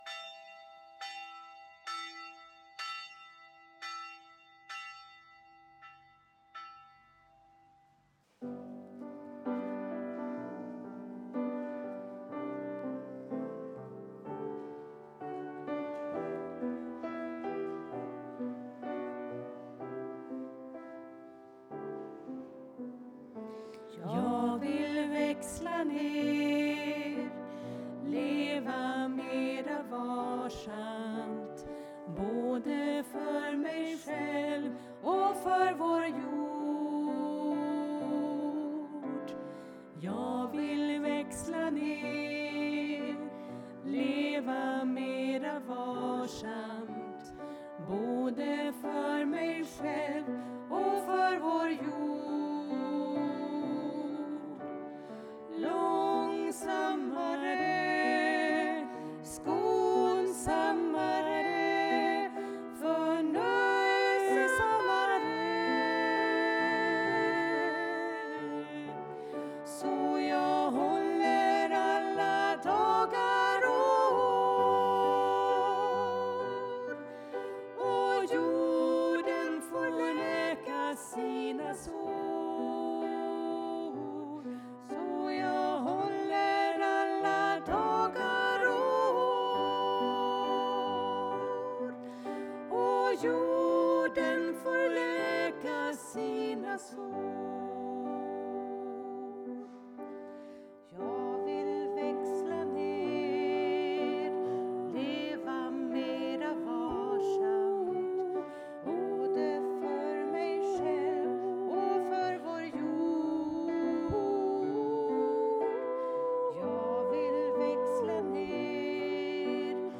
Detta är från vår klimatgudstjänst som arrangerades av vår grön kyrkas arbetsgrupp.